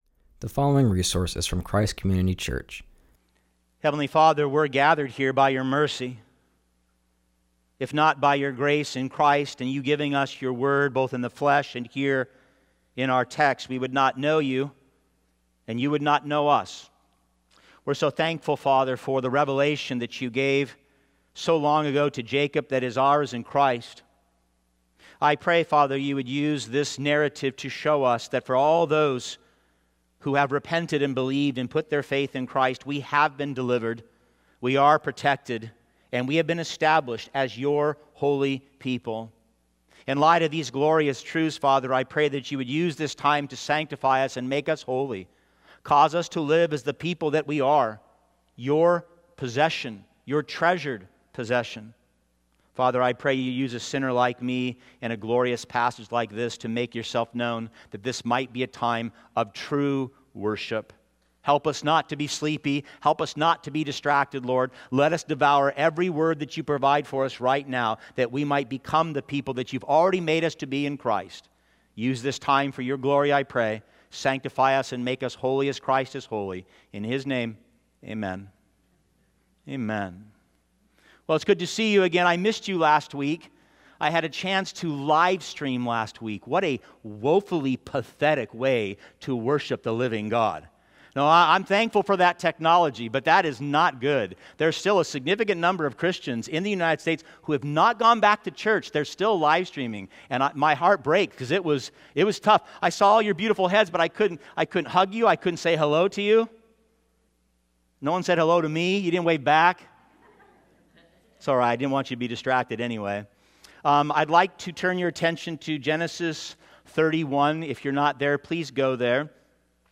continues our series and preaches from Genesis 31:17-55.